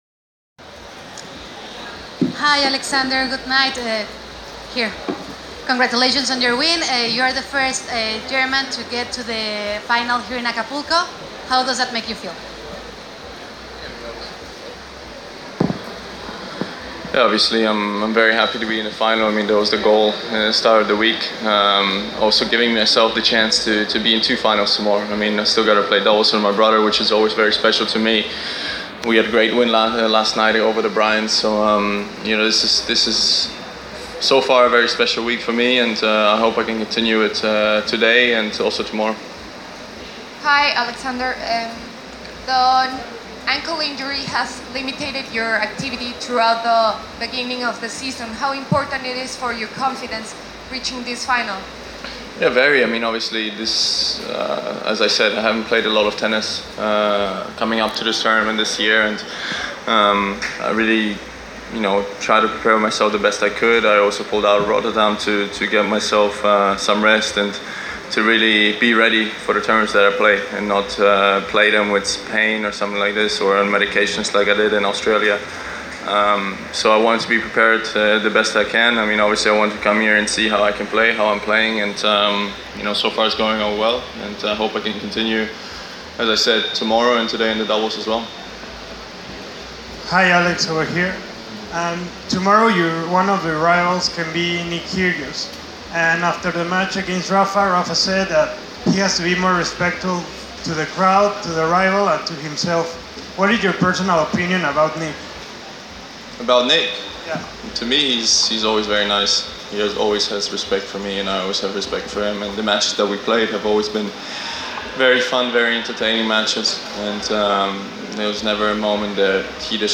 Press Conference – Alexander Zverev (01/03/2019)